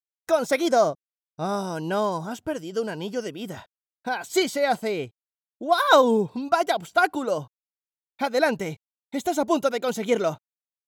Spanish speaker, voice over, young voice, voice actor, station voice.
kastilisch
Sprechprobe: Industrie (Muttersprache):